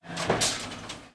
machine_attack1.wav